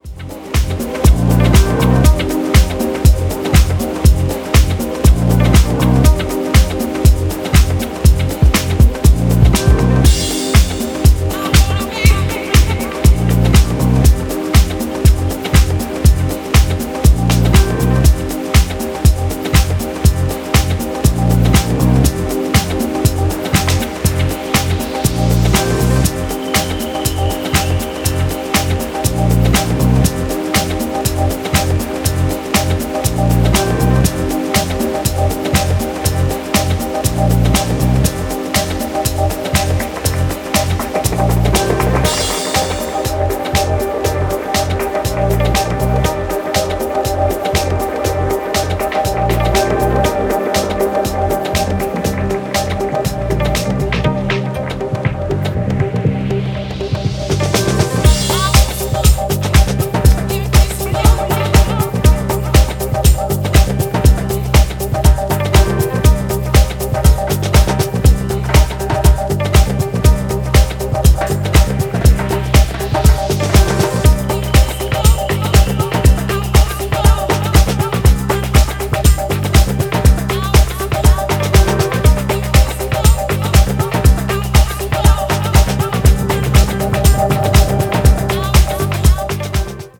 ジャンル(スタイル) DISCO HOUSE / DEEP HOUSE / BALEARIC HOUSE